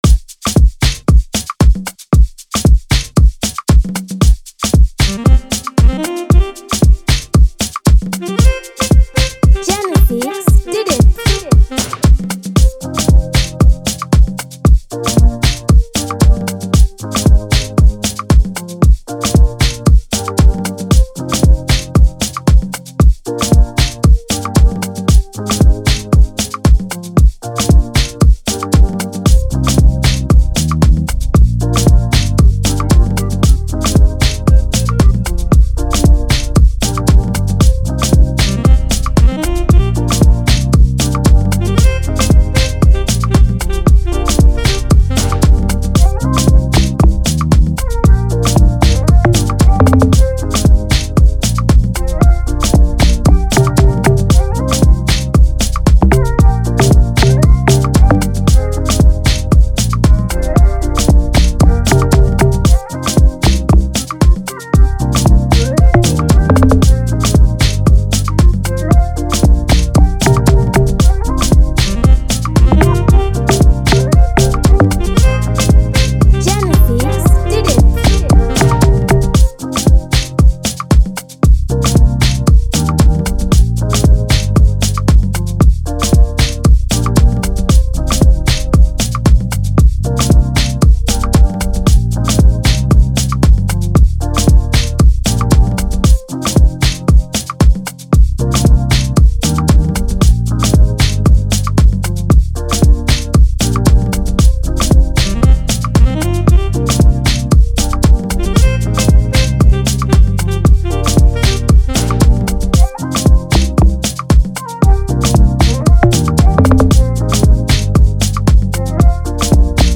Amapiano instrumental beat